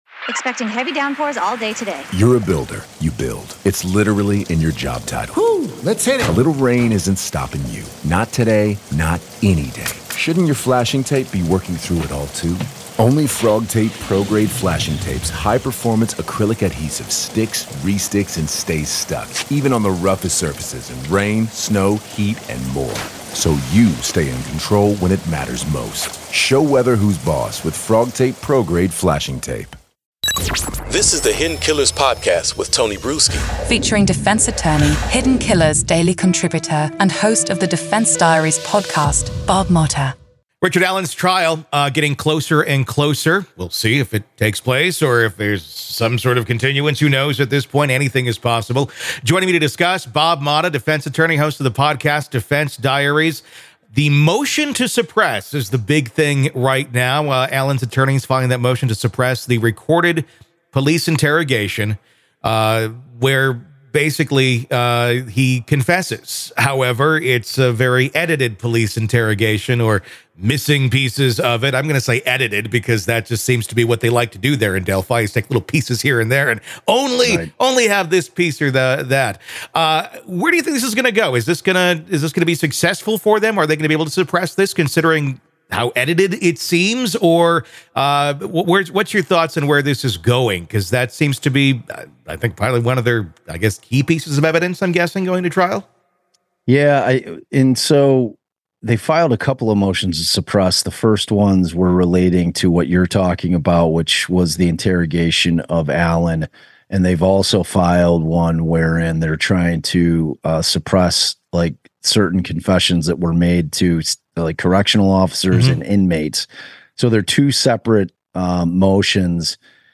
Main Points from the Conversation